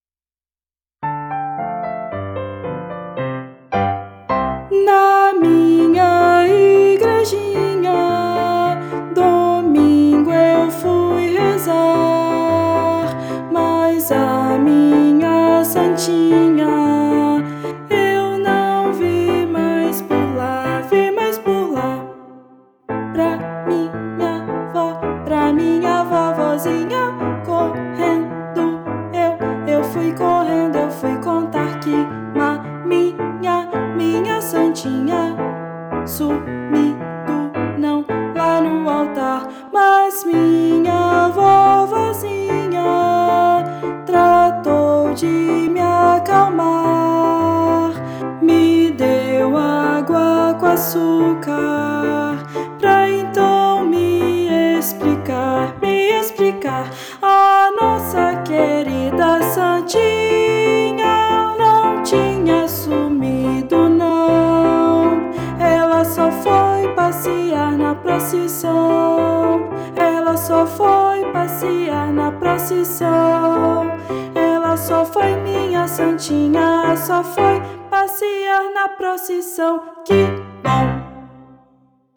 Voz Guia 2